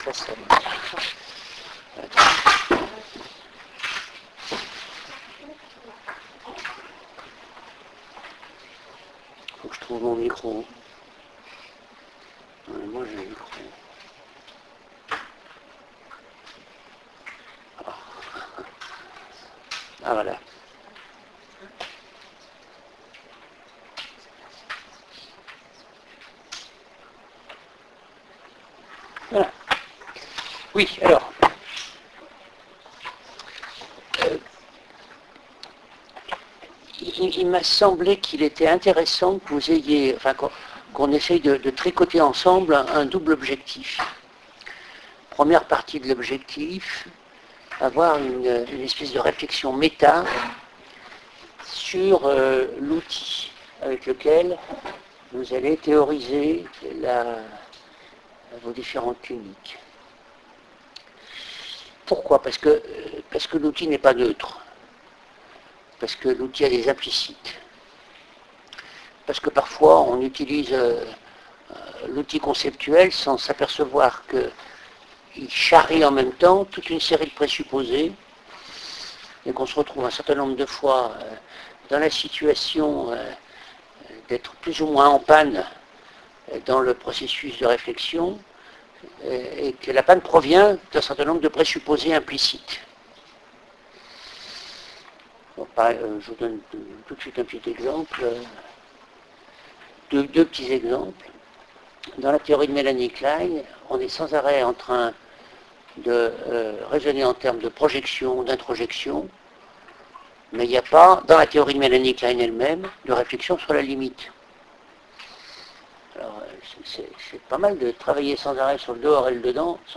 René ROUSSILLON – Cours – Master II Recherche – 1 – 24 Octobre 2013